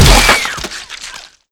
body_medium_impact_hard3.wav